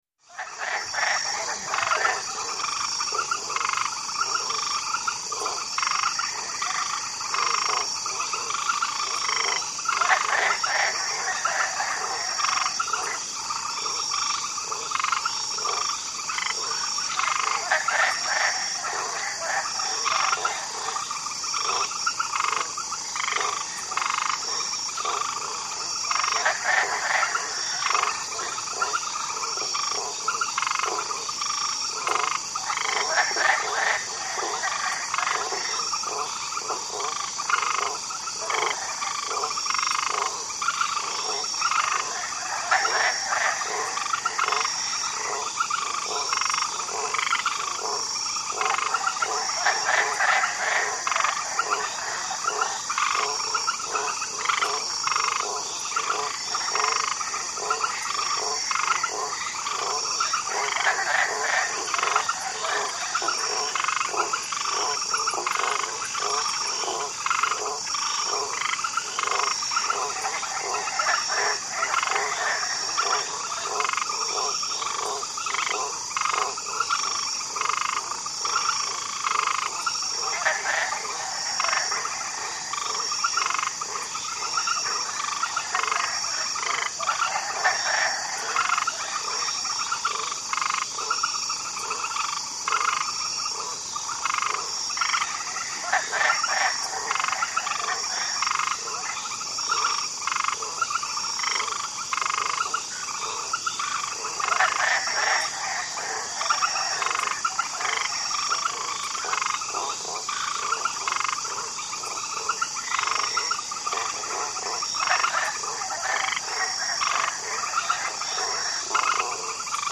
SwampAmbienceLarg PE012401
Swamp Ambience 1; Large Chorus Of High And Low Pitched Frogs Croak, Close Perspective. Insects Chirp, Medium Distant Perspective; Active.